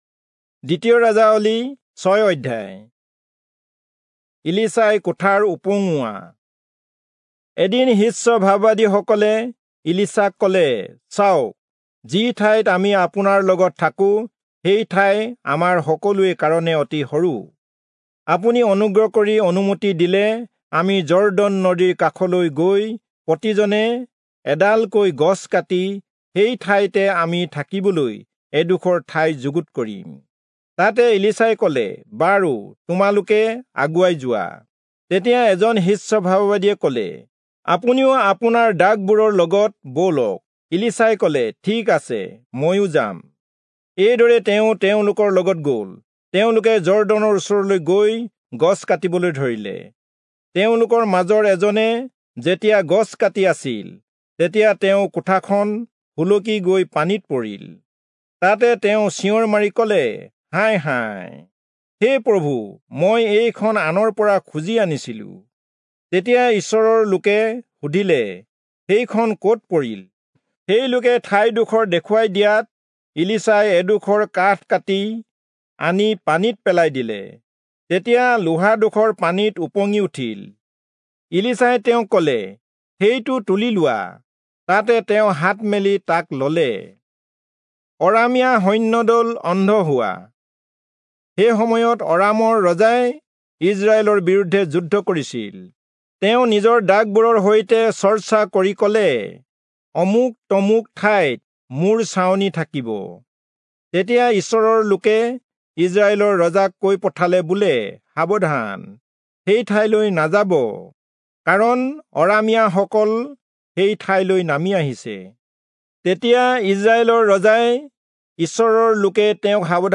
Assamese Audio Bible - 2-Kings 3 in Web bible version